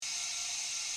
spray.mp3